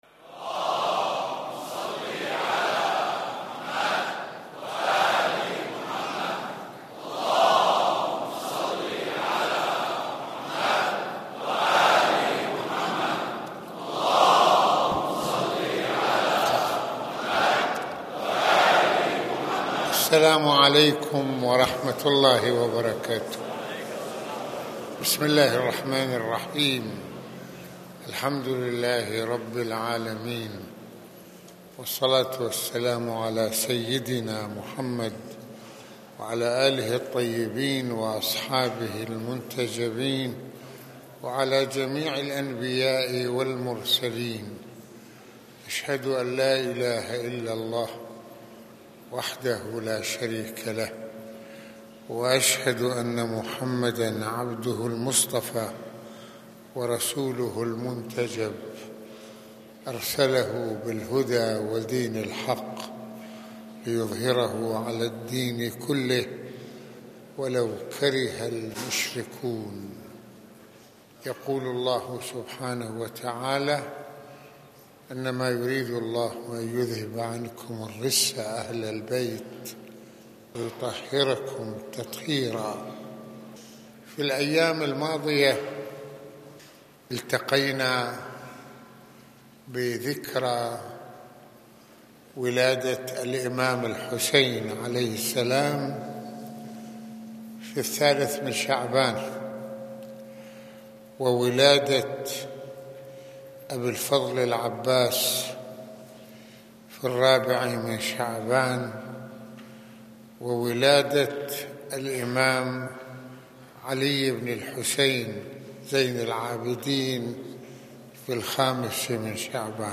خطبة الجمعة
مسجد الإمامين الحسنين(ع)